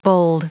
Prononciation du mot bold en anglais (fichier audio)
Prononciation du mot : bold